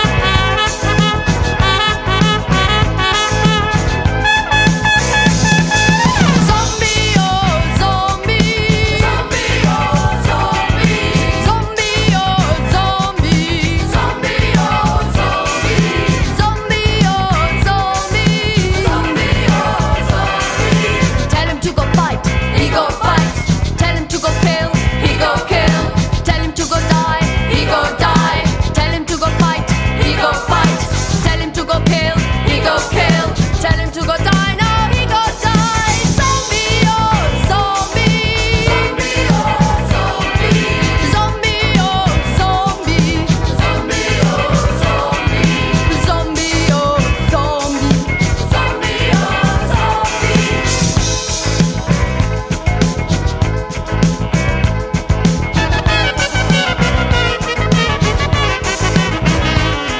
New Wave and Post Punk collided with Afrobeat